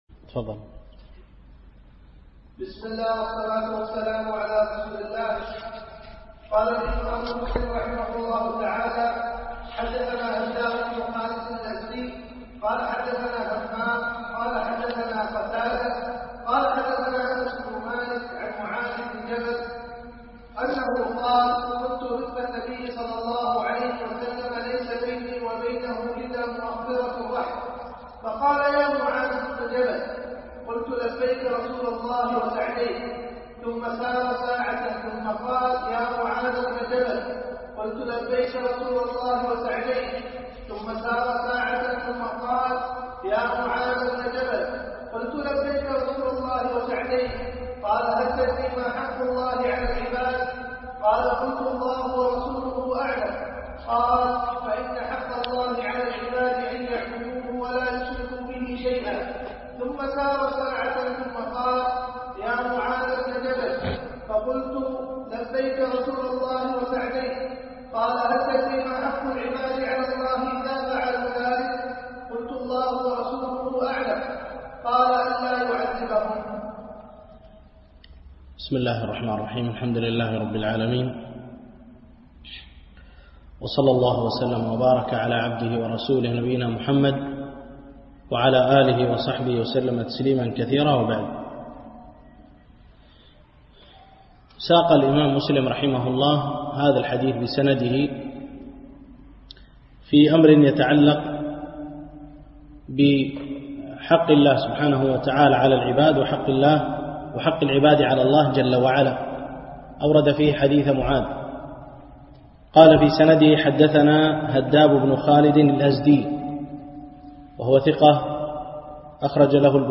دروس مسجد عائشة
التنسيق: MP3 Mono 22kHz 32Kbps (VBR)